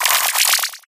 pincurchin_ambient.ogg